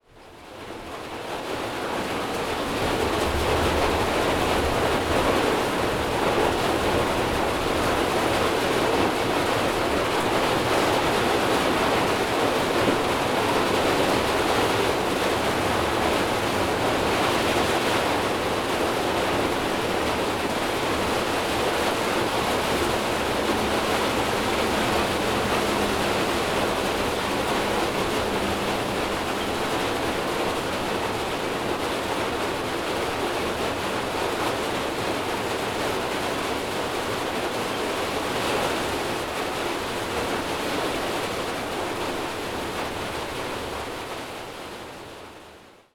I was glad to be sheltered, as it rained hard, with deep booms of thunder in the distance.
Here is a recording of the rain on the roof:
mauritania_rain_from_desert_hut.mp3